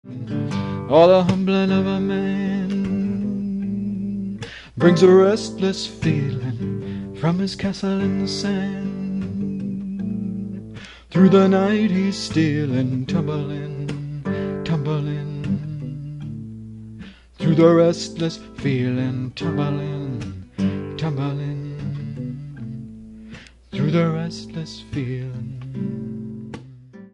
Recorded direct to cassette in Richmond Beach.
Binaural recordings